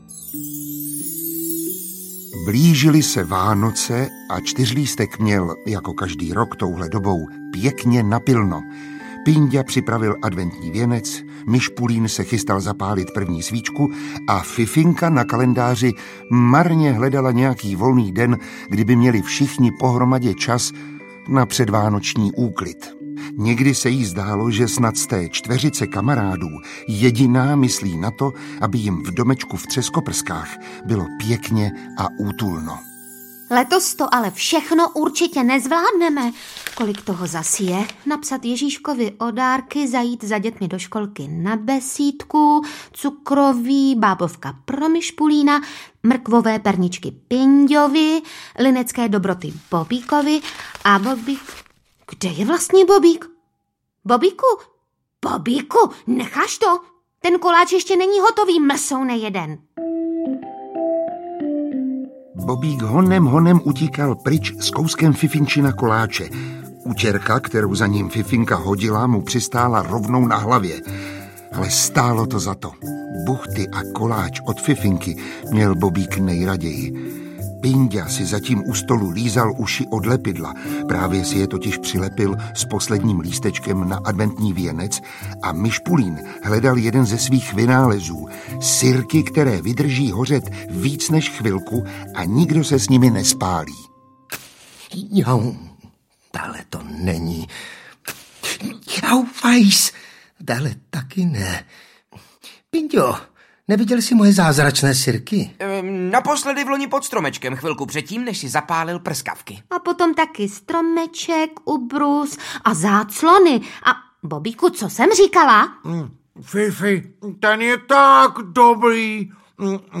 Herci Ivan Trojan, Miroslav Táborský, Ondřej Brzobohatý, Tereza Bebarová a Bohdan Tůma v hlavních rolích nových příběhů na motivy populárního komiksu.
Ukázka z knihy
Hlasy oblíbeným postavičkám propůjčili herci známí z filmového zpracování a nahrávka jistě potěší nejen děti, ale i jejich rodiče a prarodiče.
ctyrlistek-nova-dobrodruzstvi-audiokniha